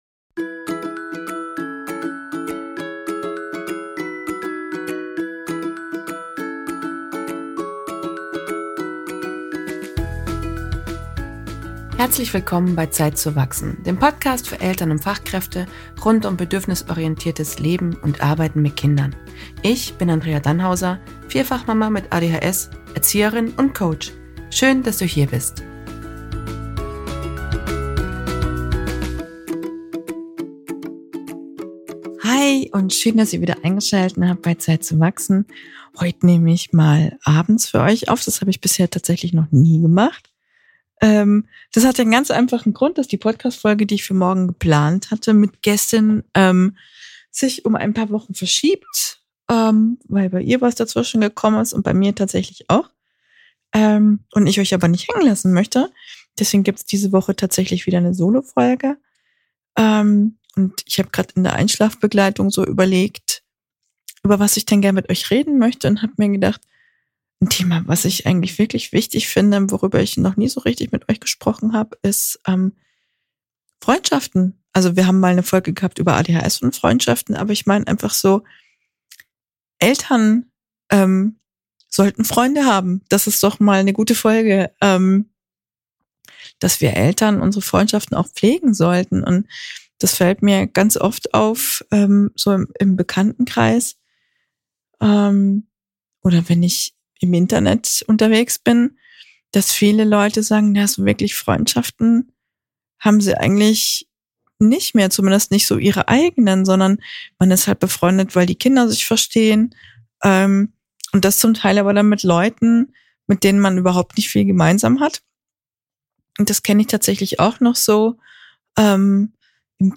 Heute spreche ich darüber, warum es für mich so wichtig ist meine Freundschaften zu pflegen und warum ich denke, dass Eltern dringend Freundschaften pflegen sollten. Kleine, feine Solofolge für alle, die ihre Freundschaften wertschätzen.